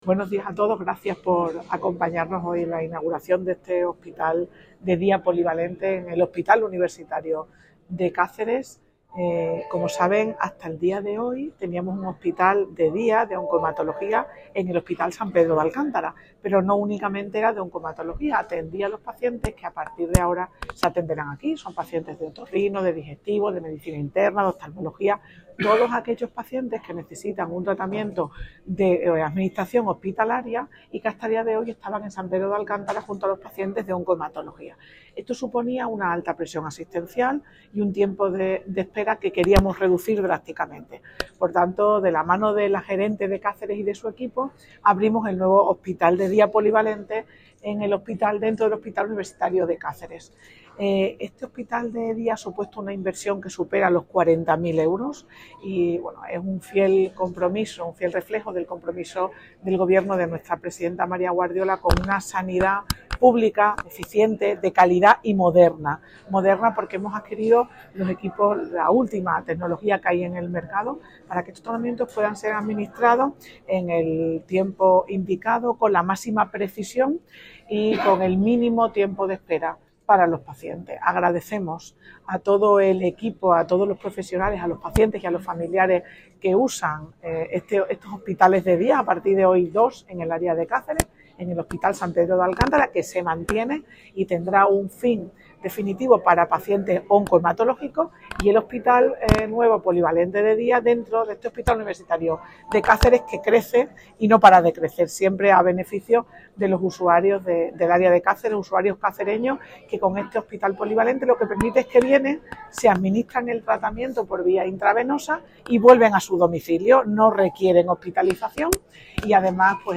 Noticias El SES invierte m�s de 40.000 euros en el nuevo Hospital de D�a Polivalente del Universitario de C�ceres 12/12/2024 SALUD EXTREMADURA C�CERES Documentos relacionados Audio de la consejera de Salud y Servicios Sociales .